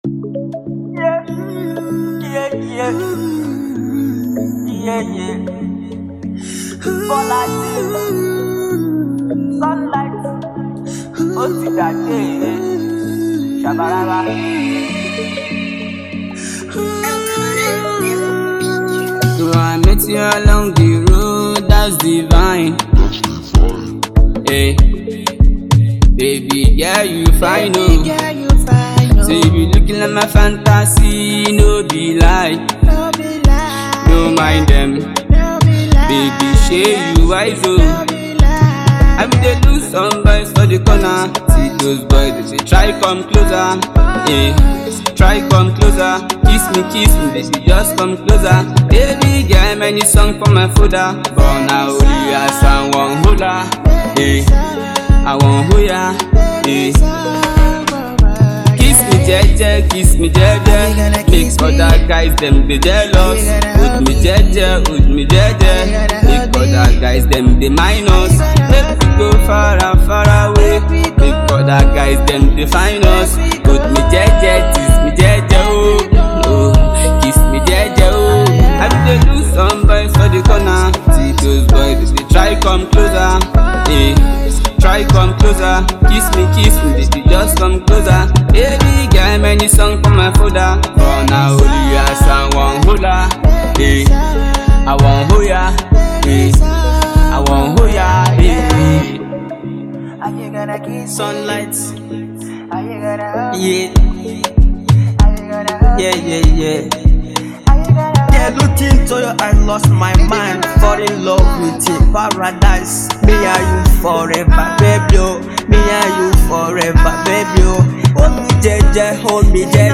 This is an Afropop track.
GENRE: Afropop